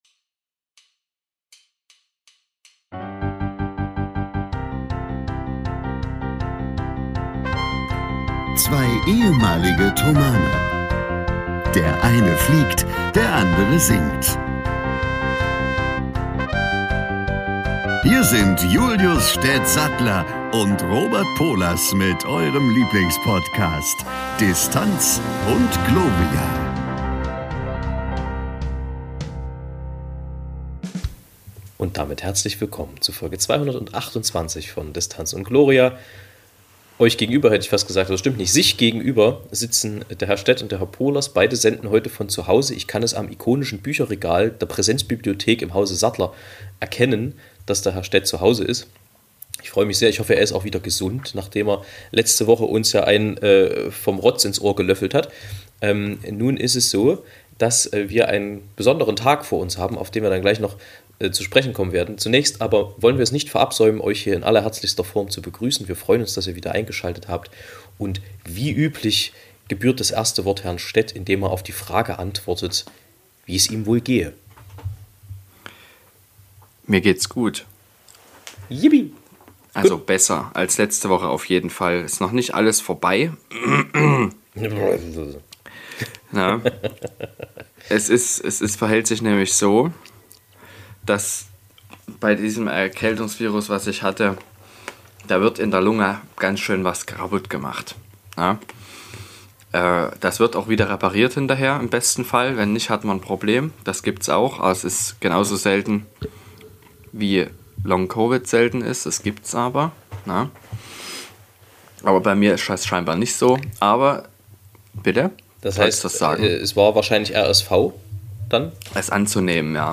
Auch heute gibt es wieder eine neue Folge D&G. Heute senden beide von Zuhause, aber das macht es nicht weniger interessant.